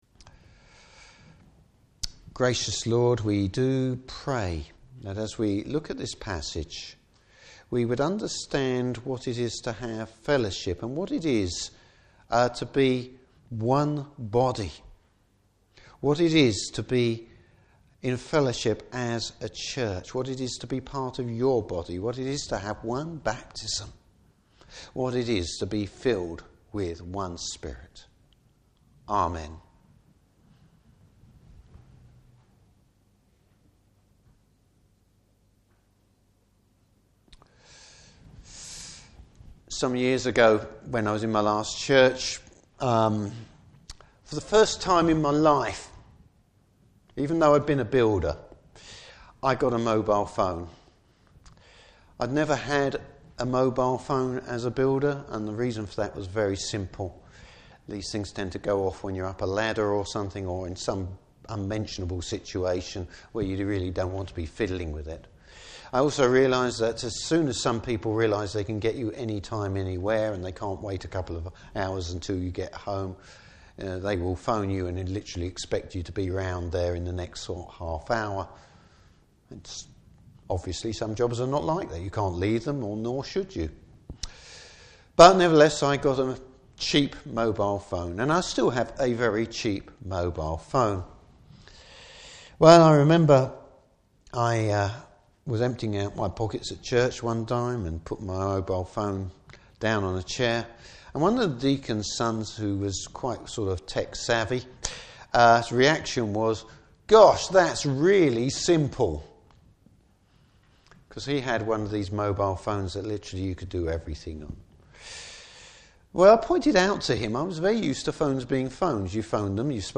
Service Type: Morning Service One Lord, one Spirit, one Church!